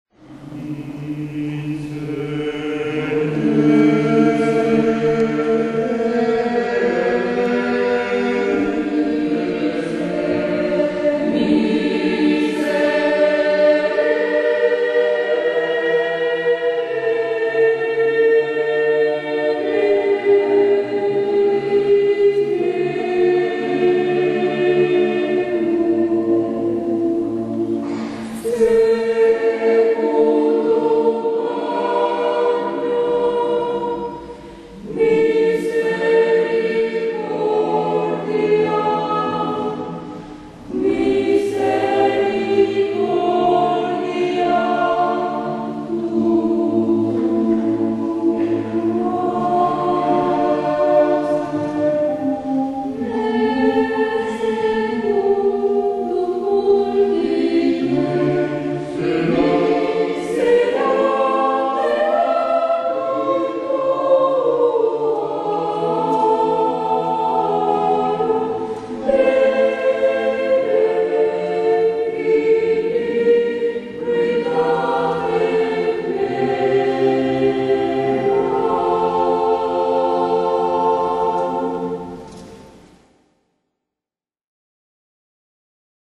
Además, el traslado estuvo acompañado en todo momento por el Coro Siarum y las voces de algunos Saeteros que se sumaron a la función.
El Coro Siarum actuó durante la función del traslado, dejando de manifiesto su alto nivel, aquí tienes un ejemplo.